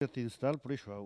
Elle provient de Sallertaine.
Catégorie Locution ( parler, expression, langue,... )